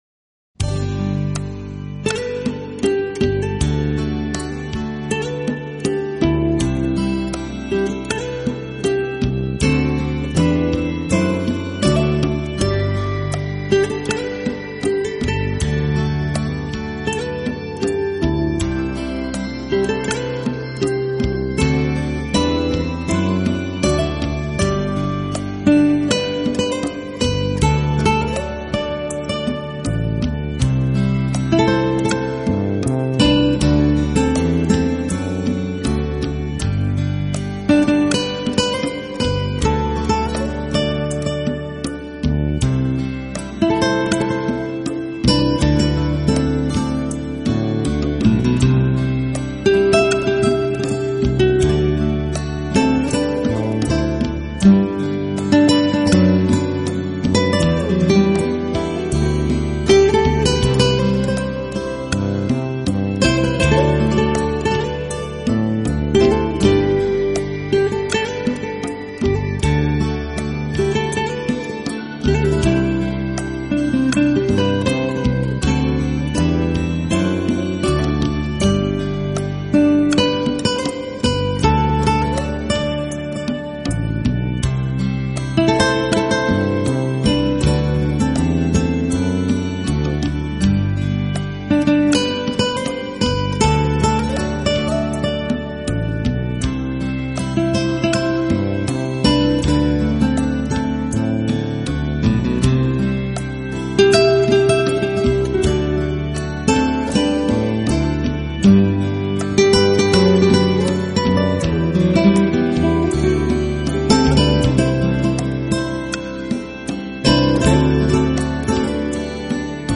音乐风格:夏威夷风
吉他少了一份欧洲人的夸张与急促，而多了热带岛屿的宁静，悠闲。